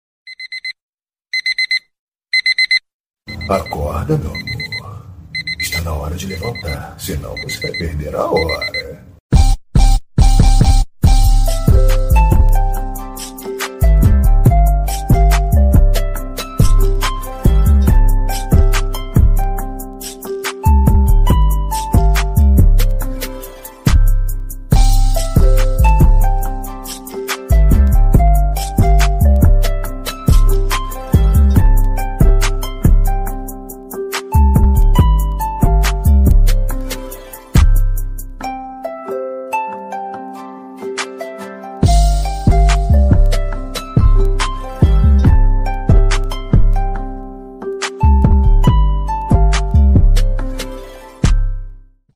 Som de alarme do Sukuna
Toque de despertador com a voz de Sukuna Som de notificação do Sukuna
Categoria: Toques
Descrição: Baixar o som de alarme do Sukuna em mp3, download o despertador com a voz do Sukuna de Jujutsu Kaisen como alarme e áudio para o seu telefone.
som-de-alarme-do-sukuna-pt-www_tiengdong_com.mp3